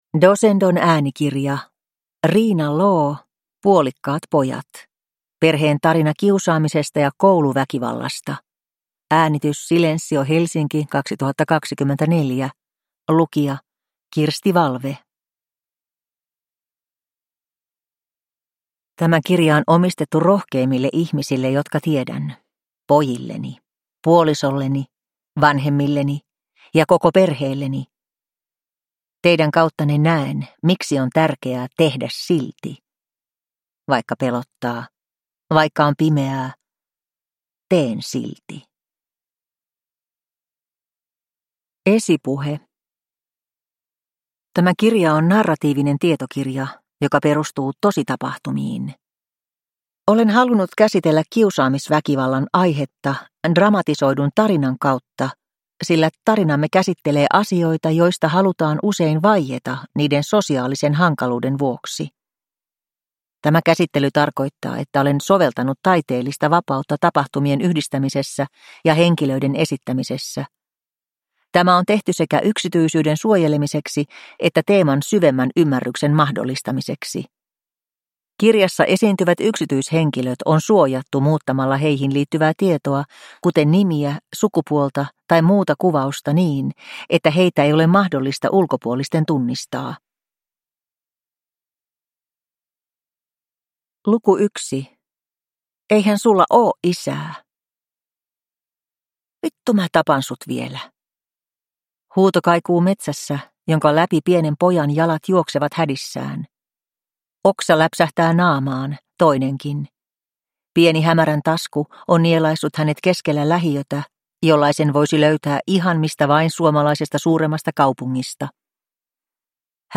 Puolikkaat pojat – Ljudbok